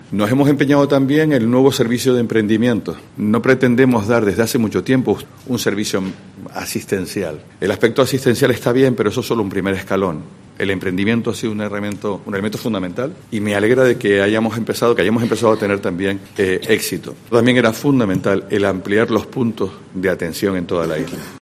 Pedro Martín explica las novedades del programa Barrios por el Empleo
Estos son algunos de los datos que se han dado a conocer en una rueda de prensa en la que se ha hecho balance de los resultados obtenidos en 2022 y donde se han presentado algunas de las novedades para este año de 'Barrios por el Empleo: Juntos más Fuertes', un proyecto del Cabildo de Tenerife que coordina la Fundación Insular para la Formación, el Empleo y el Desarrollo Empresarial (FIFEDE).